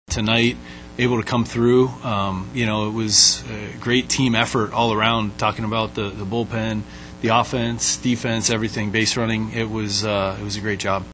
Pirates manager Don Kelly called it a great win for the Bucs.